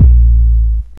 808s
Bass (7).wav